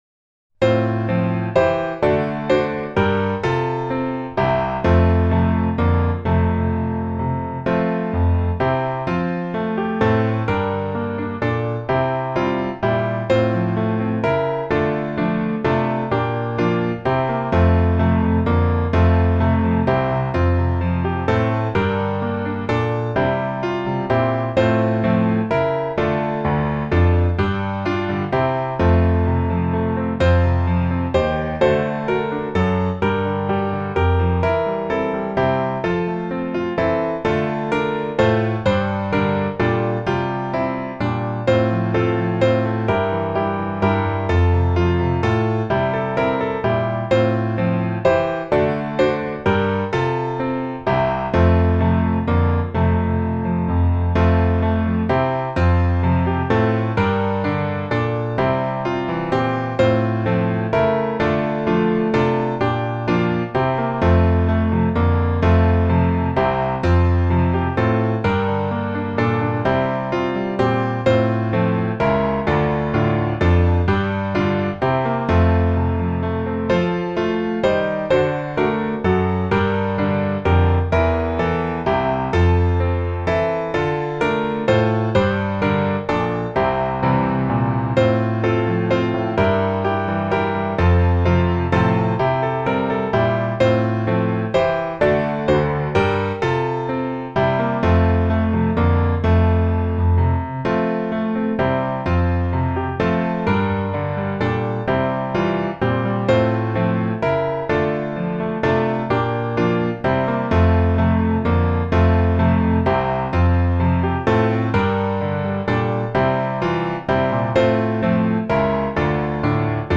F大調